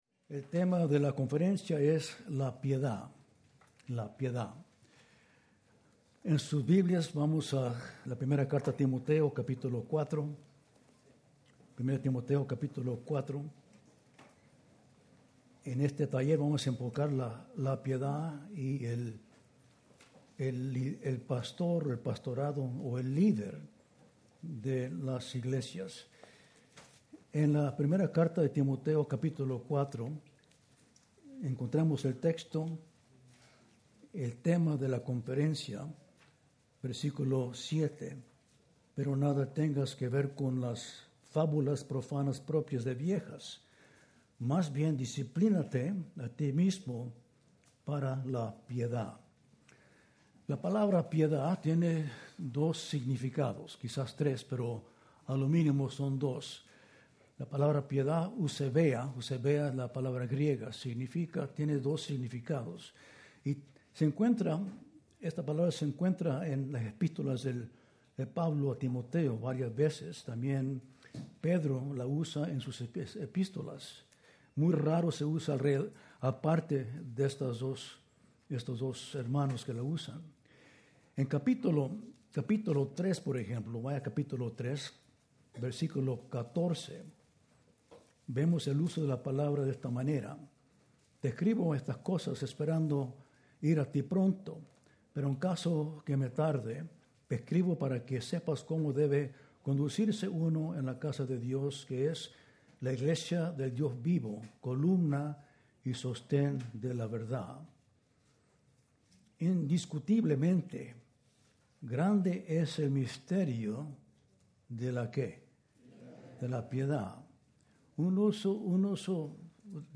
Taller: La piedad en el ministerio pastoral | Conferencia Expositores | Grace Community Church